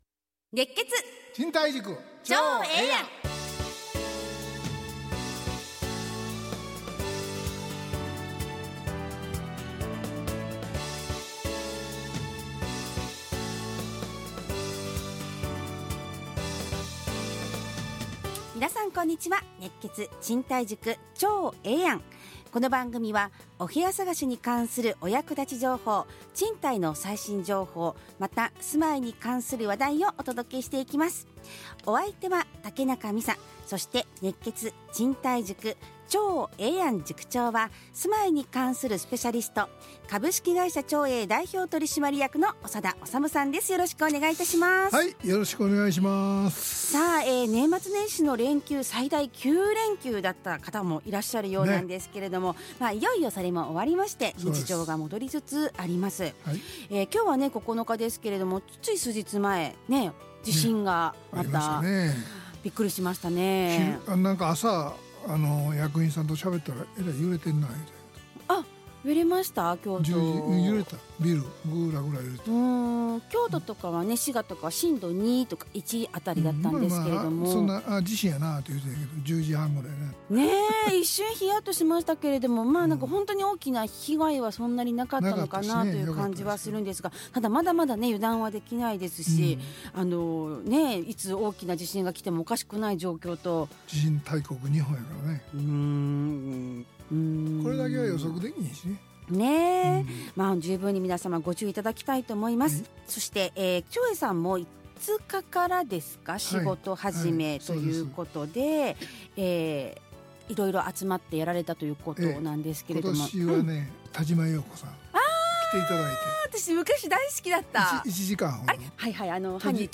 ラジオ放送 2026-01-09 熱血！